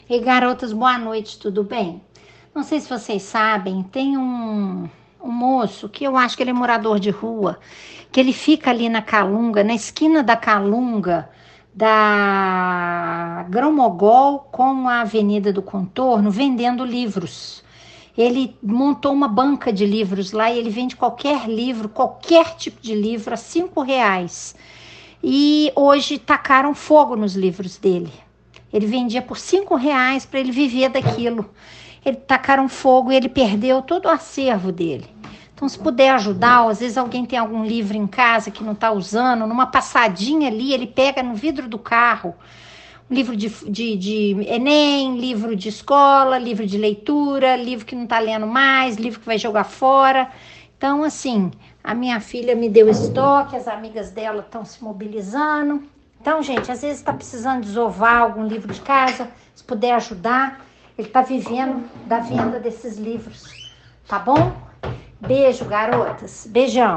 Ouçam gravado o depoimento de uma pessoa com sensibilidade e solidariedade suficientes para denunciar este ato desumano e fazer uma campanha humanitária em prol deste TRABALHADOR!